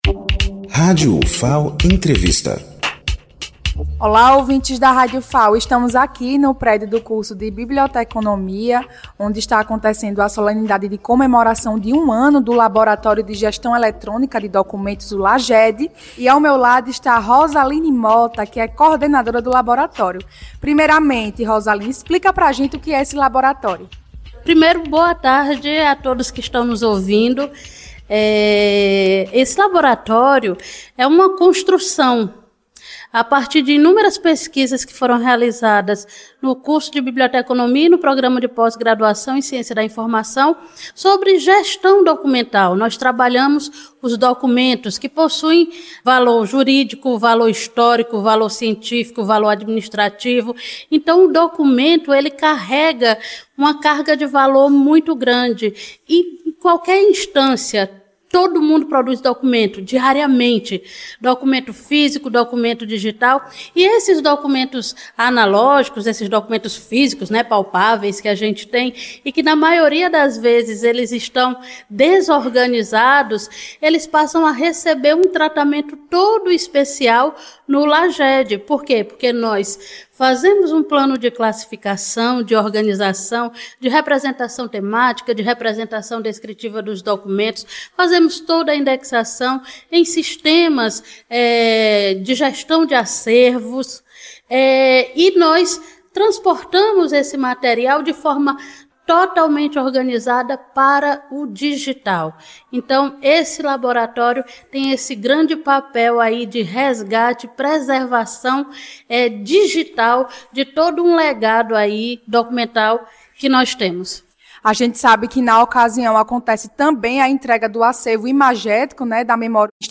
Entrevista
Direto do prédio do curso de Biblioteconomia, a Rádio Ufal acompanha a solenidade de comemoração de um ano do Laboratório de Gestão Eletrônica de Documentos (LAGED).